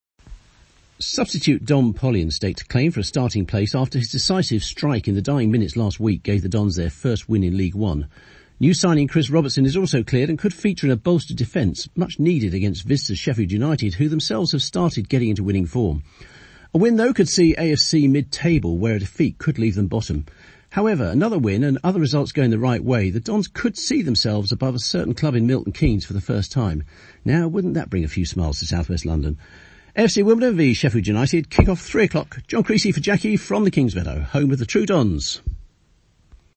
the preview of the game between AFC Wimbledon and Sheffield United from Kingsmeadow.